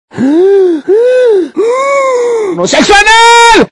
Tono para móvil un poco salvaje.